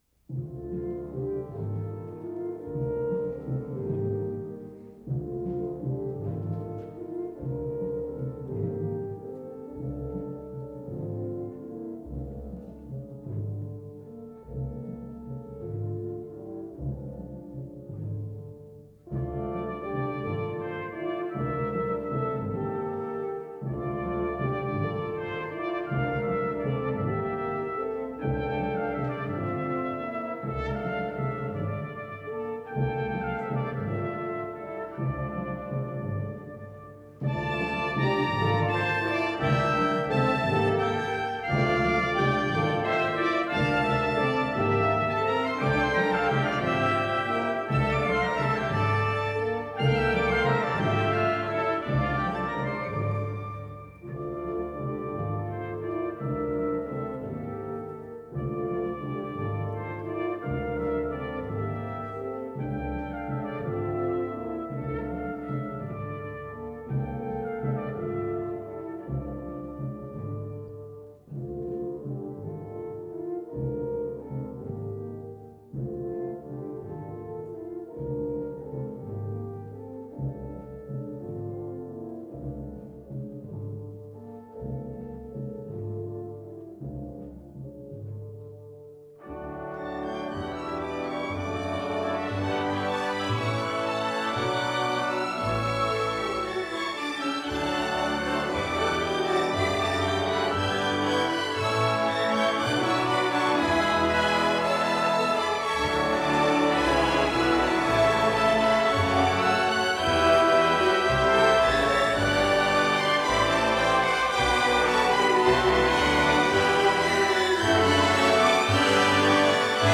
Symphony Hall, Boston